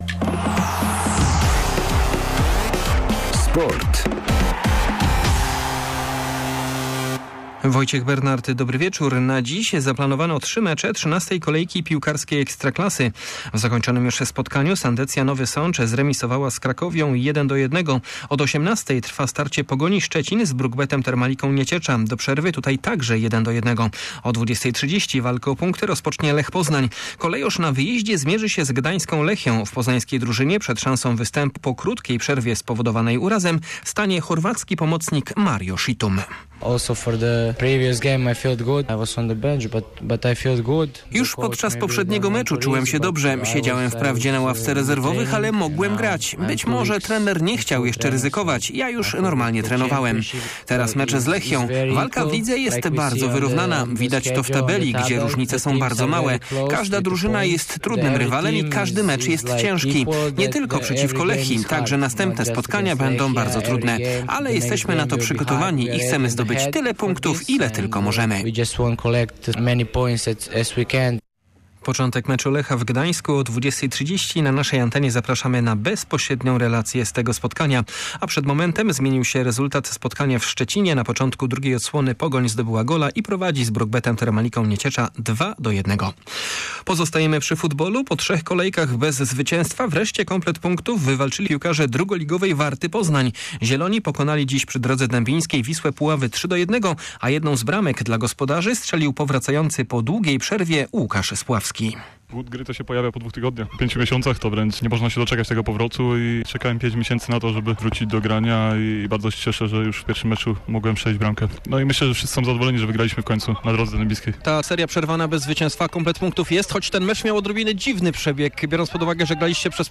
21.10 serwis sportowy godz. 19:05